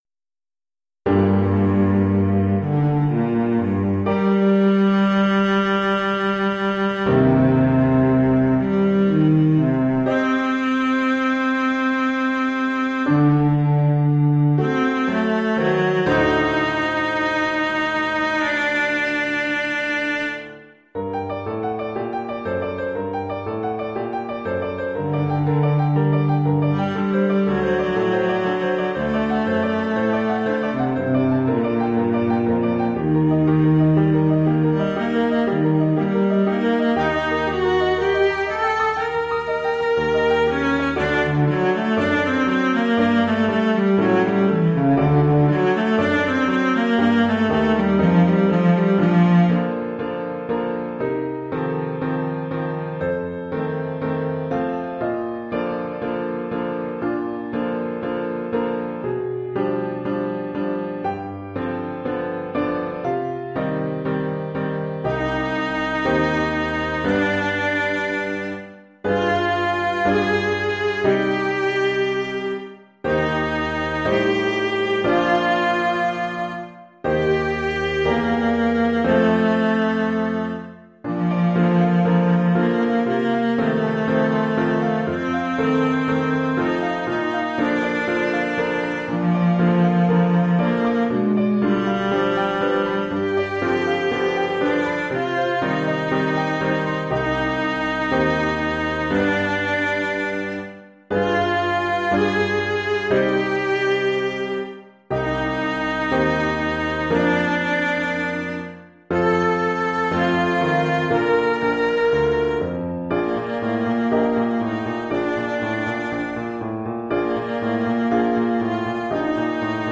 Formule instrumentale : Violoncelle et piano
Oeuvre pour violoncelle et piano.